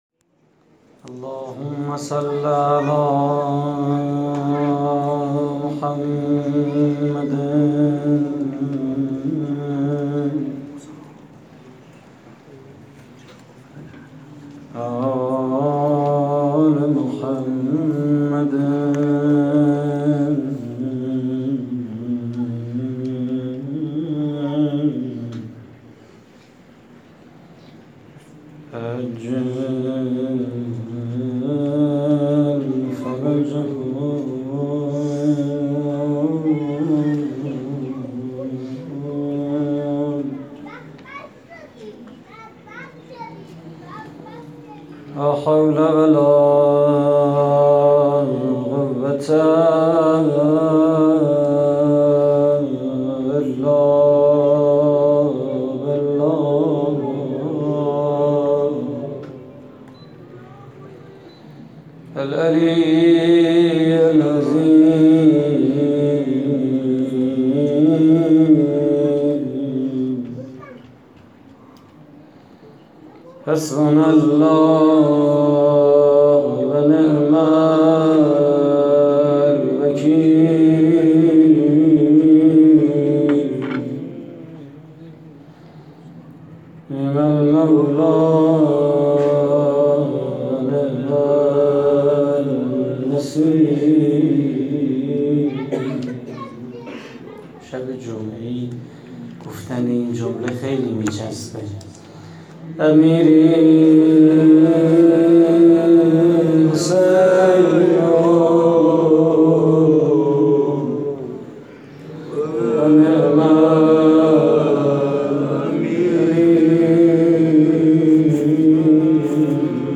مناجات: باشد قبول من بدم
مراسم عزاداری شهادت امام صادق (ع)